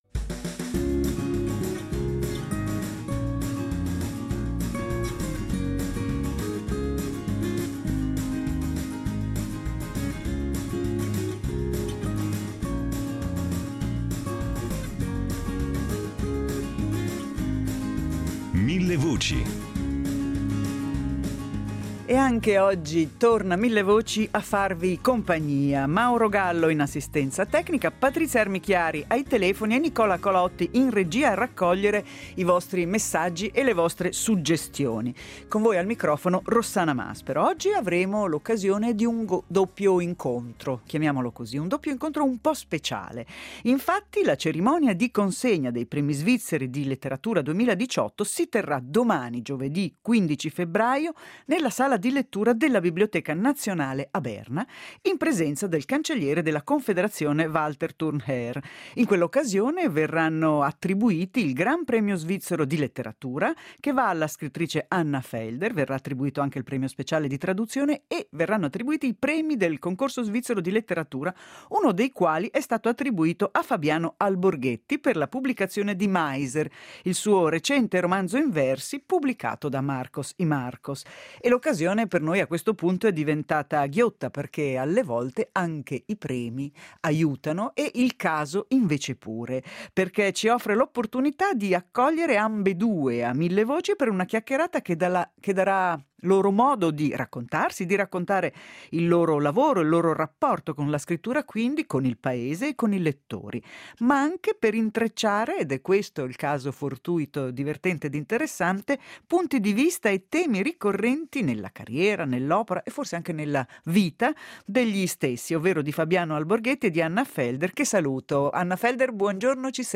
doppia intervista